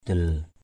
/d̪il/ 1.